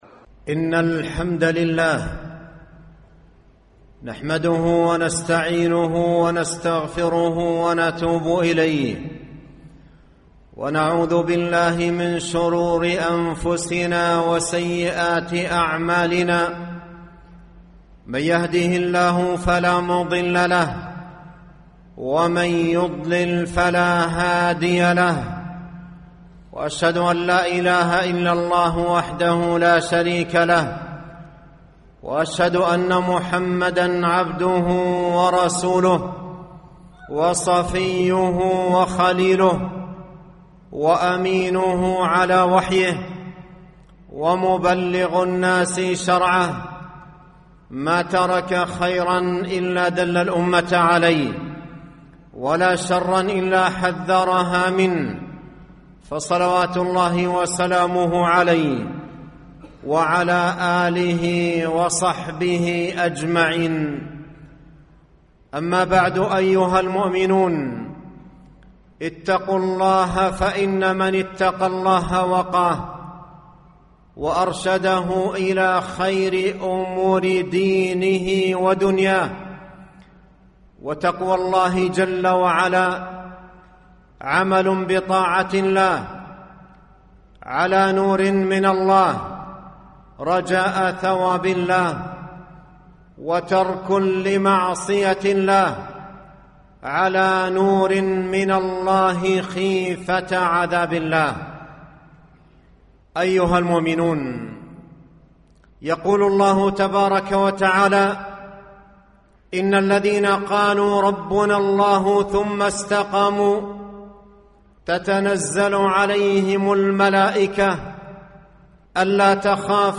خطب مواعظ